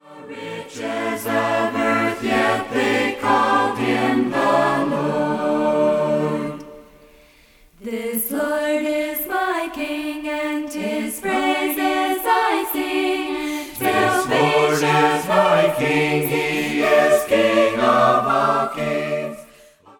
A cappella mixed group sings many beautiful hymns.